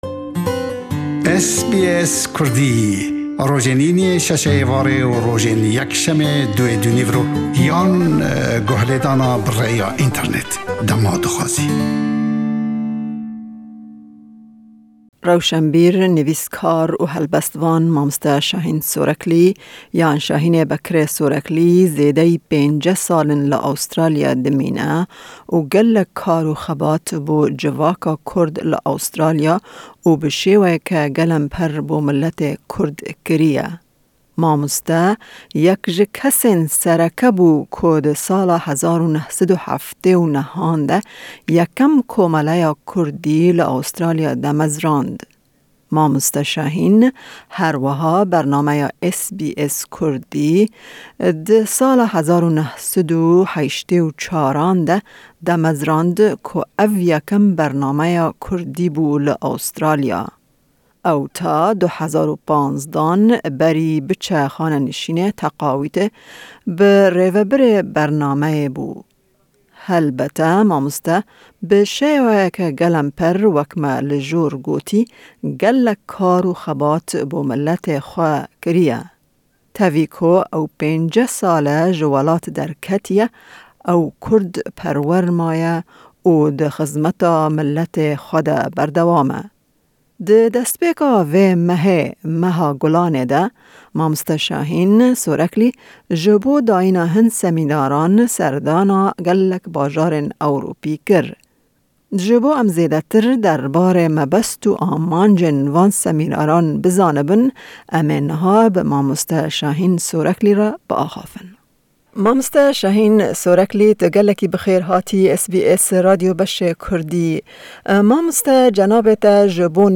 Me hevpeyvînek derbarê serdana wî ya Ewropa pêk anî.